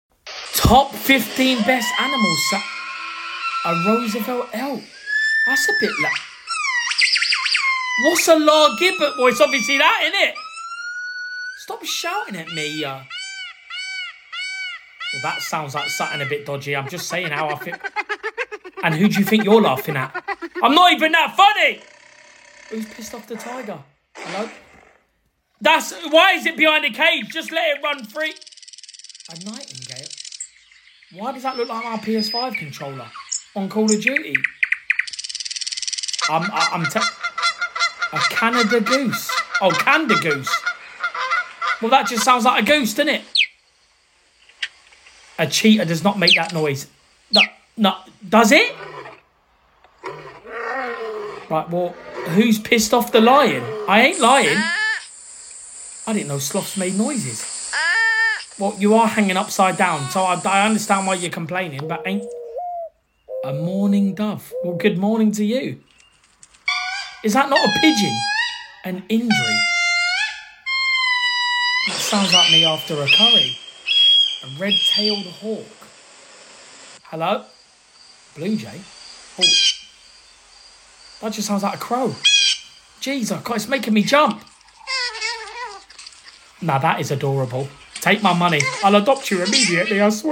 SOME OF THESE ANIMAL SOUNDS ARE CRAZY
You Just Search Sound Effects And Download. funny sound effects on tiktok Download Sound Effect Home